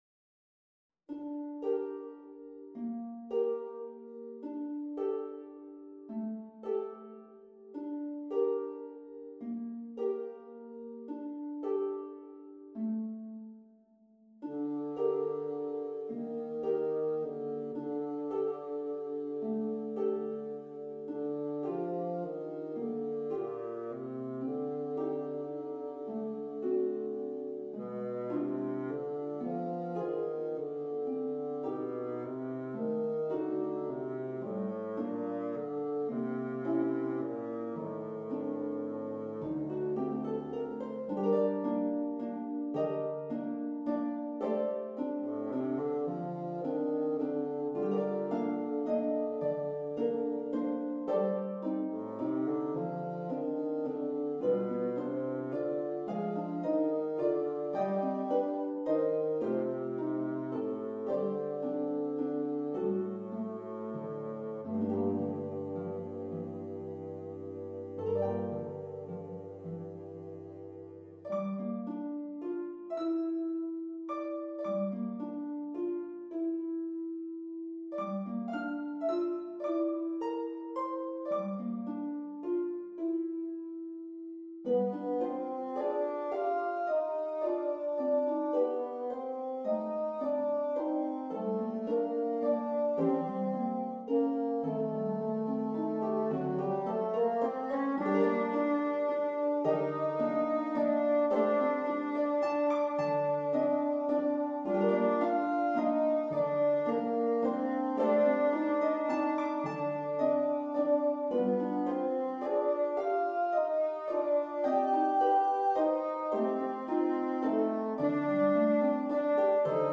for bassoon or cello and harp